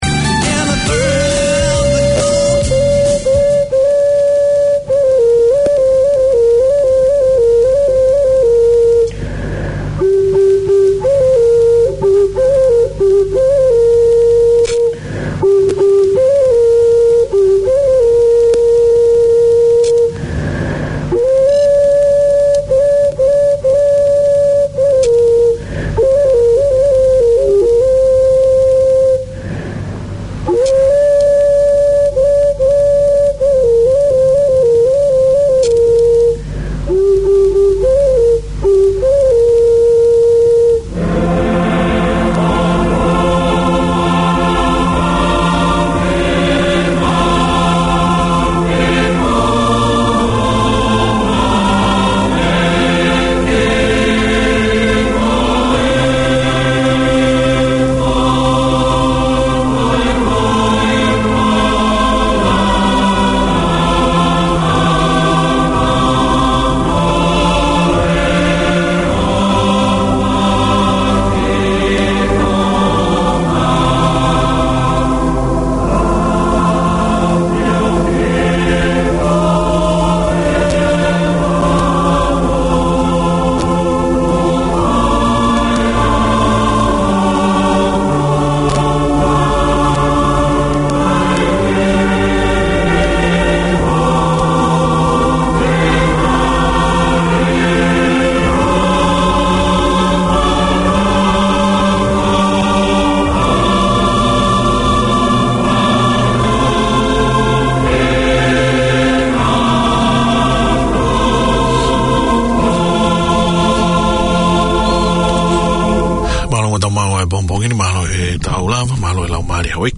The hot Tongan News show, Akiheuho canvases current affairs of concern to Tongans airing in-depth interviews with Tongan figureheads, academics and successful Tongans from all walks of life. Covering the world and Pasefika from a Tongan viewpoint.